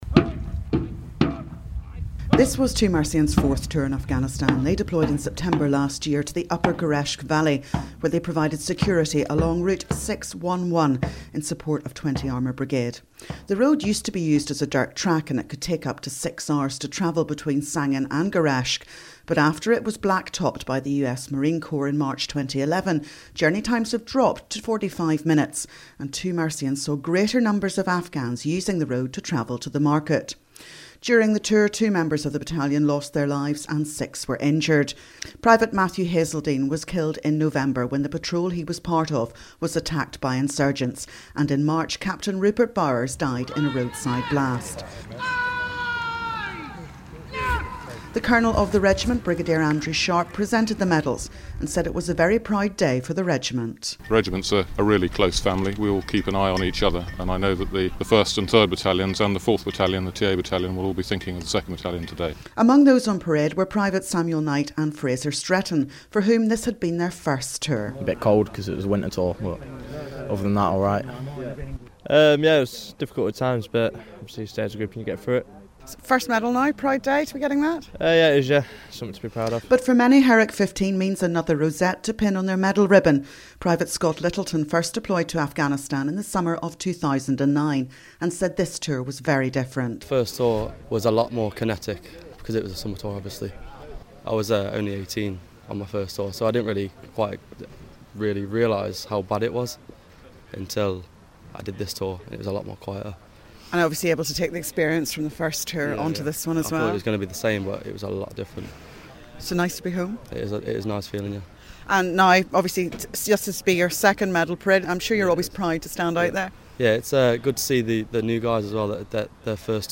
Yesterday 2 Mercian received their operational medals following Herrick 15 at Palace Barracks in Holywood